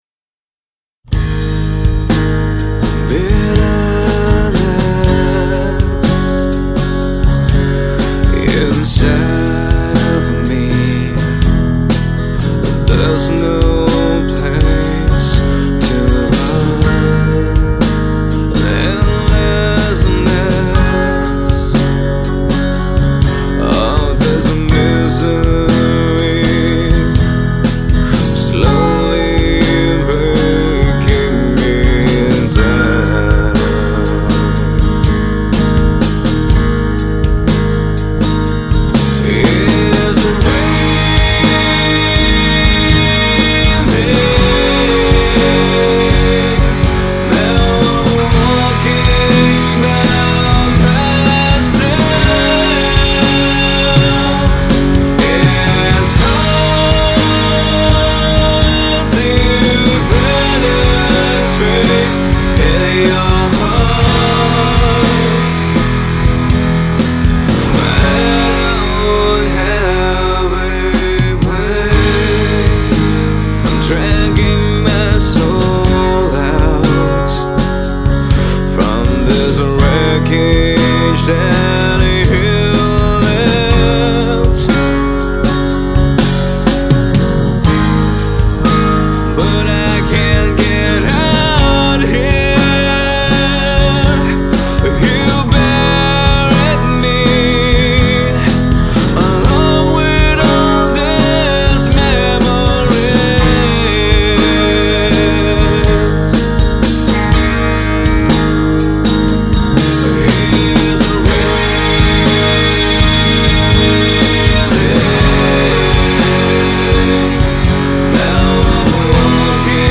Singer | Songwriter
soul-stirring vocals accompanied by an acoustic guitar